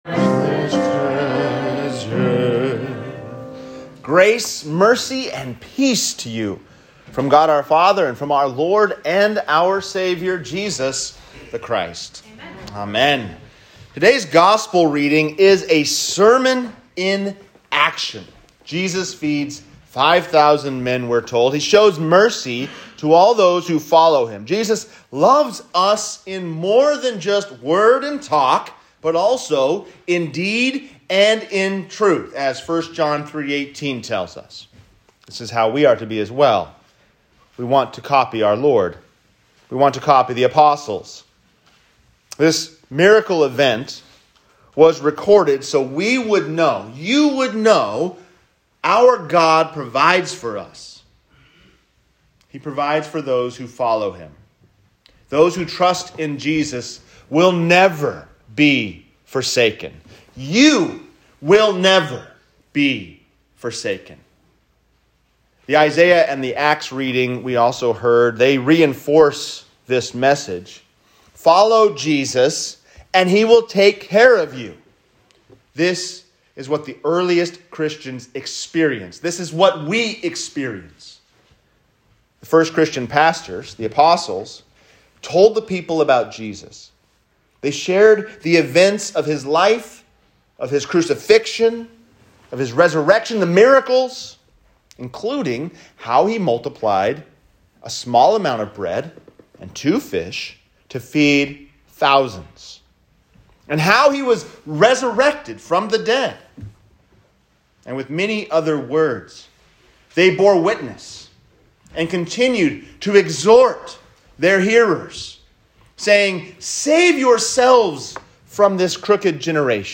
3-27-22-sermon_laetare.m4a